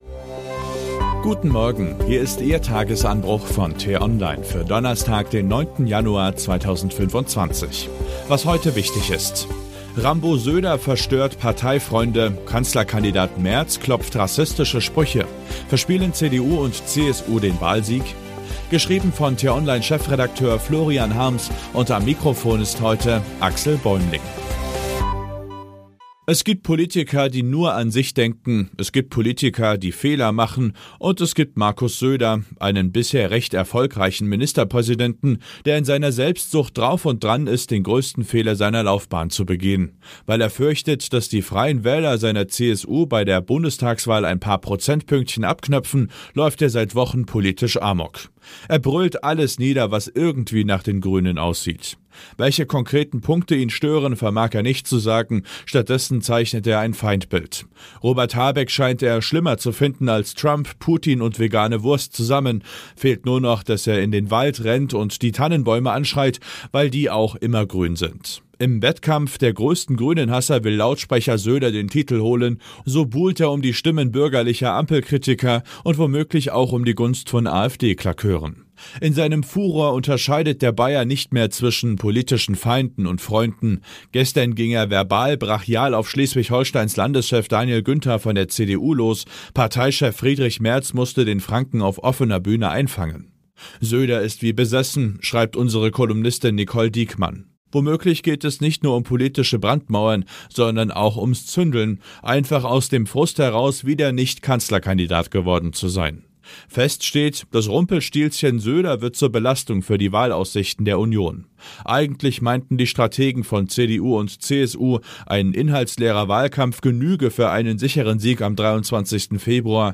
Der Nachrichtenpodcast von t-online zum Start in den Tag.